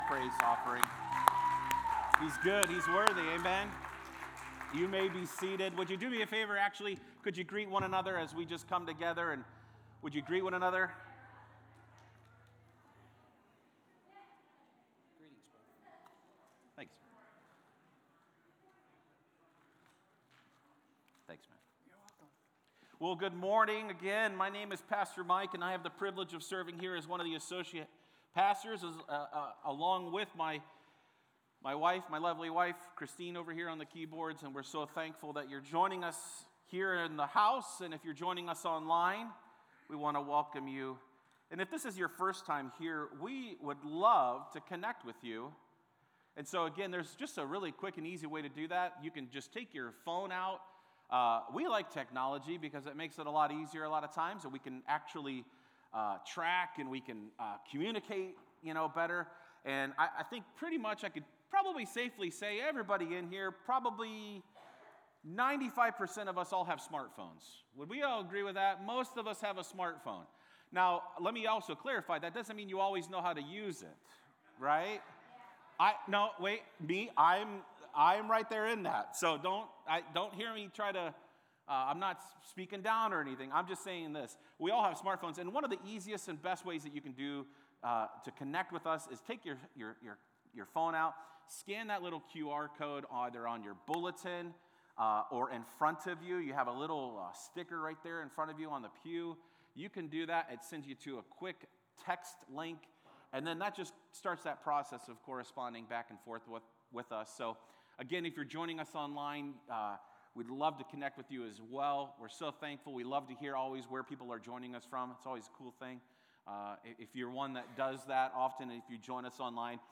A message from the series "Let Loose."